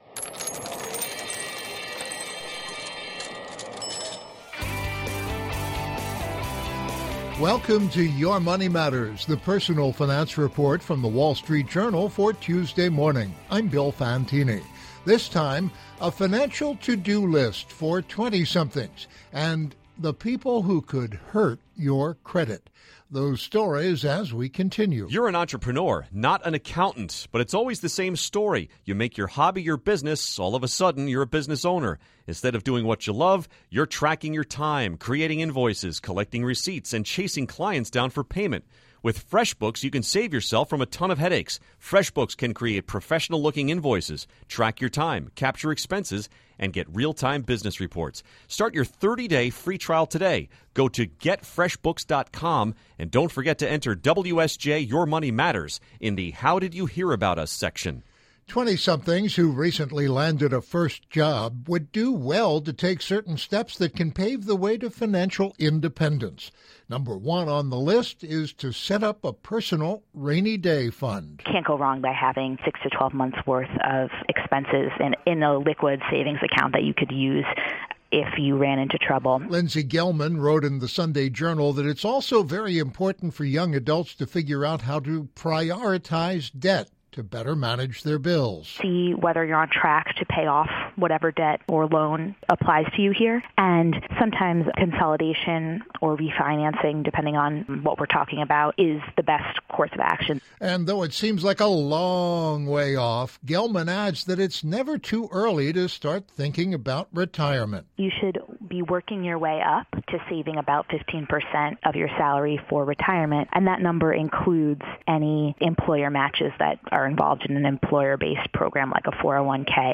reports on steps 20-somethings can take toward financial independence. Also - the people who can hurt your credit score.